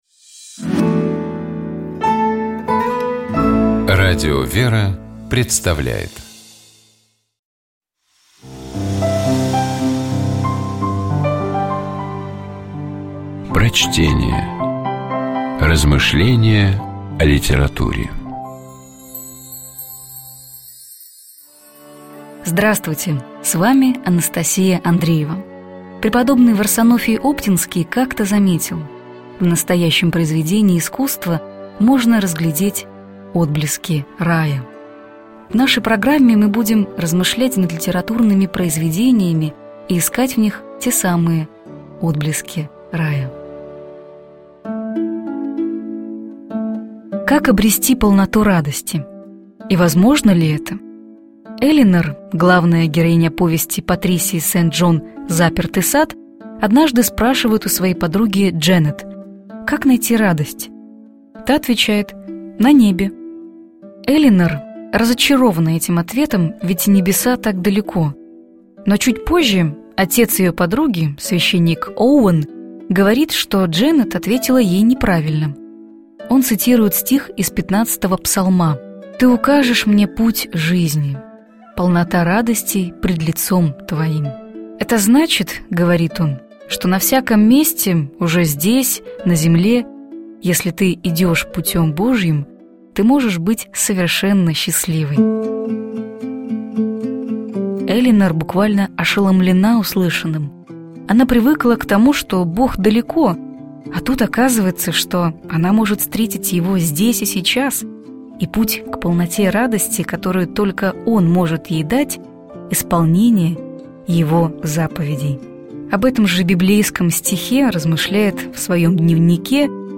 Prochtenie-P_-Sent-Dzhon_-Zapertyj-sad-Polnota-radosti.mp3